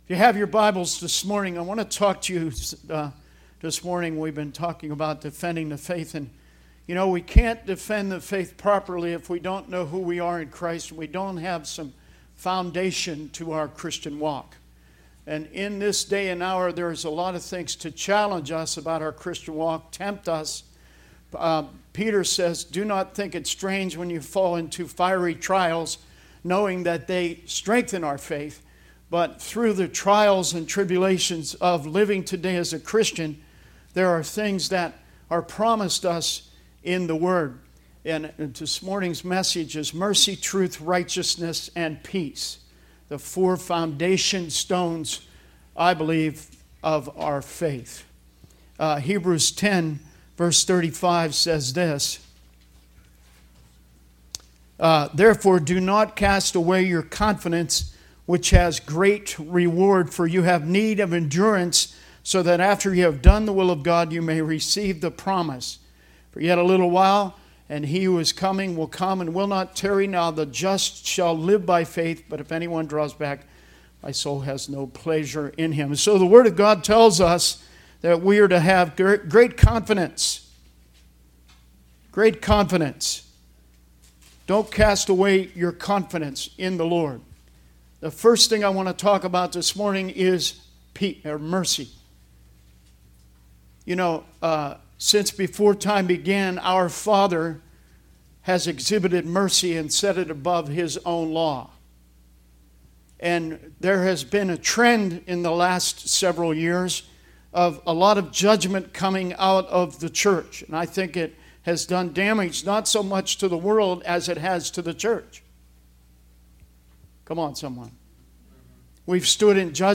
Sermon messages available online.
Service Type: Sunday Teaching